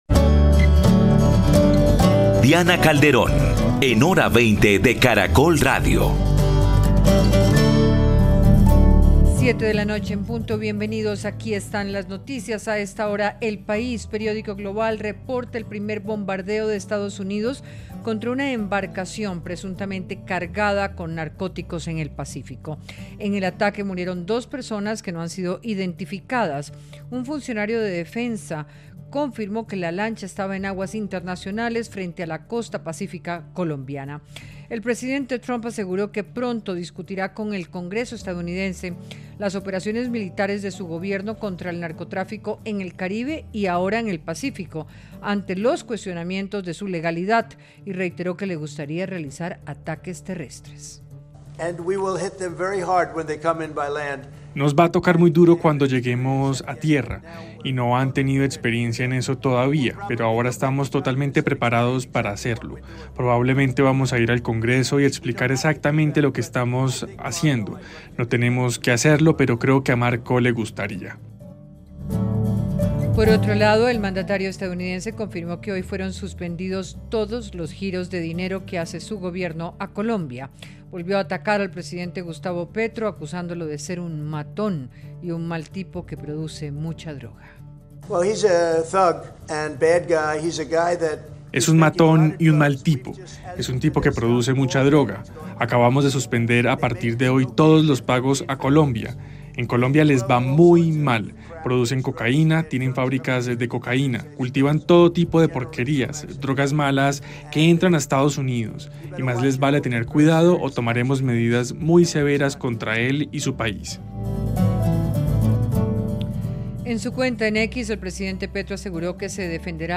Panelistas analizaron lo que viene para Colombia y el mensaje que se envía al gobierno Petro ahora que se registró el primer hundimiento a una presunta narcolancha en el Pacífico.